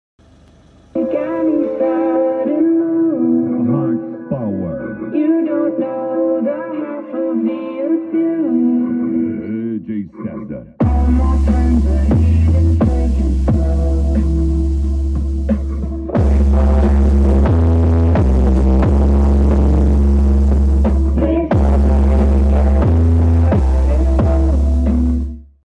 Testlåda till 2st brutala kl audio 12” middar som släpps i framtiden, låda med element finns till salu.